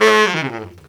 Index of /90_sSampleCDs/Best Service ProSamples vol.25 - Pop & Funk Brass [AKAI] 1CD/Partition C/BARITONE FX2